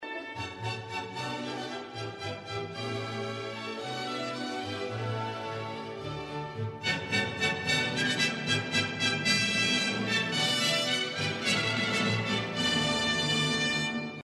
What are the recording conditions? But I can notice a slightly lower quality in the 96 kbps mp3 file.